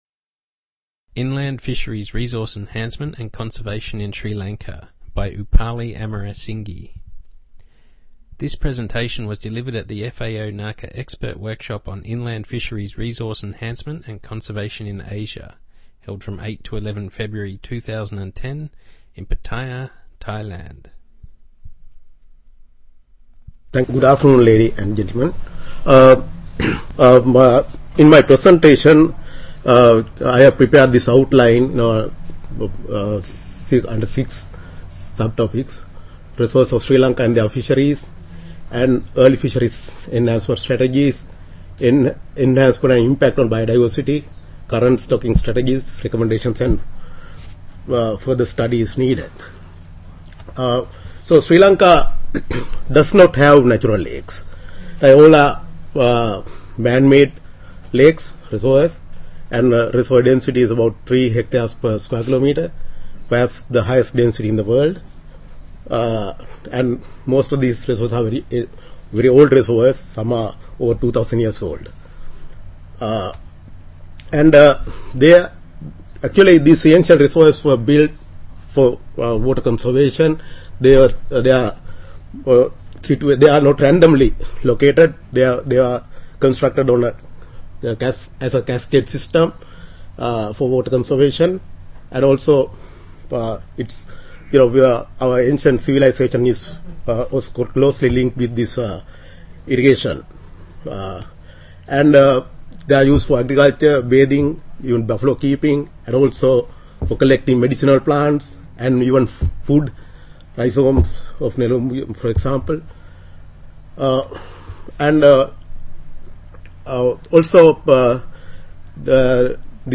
Presentation on inland fisheries resource enhancement and conservation in Sri Lanka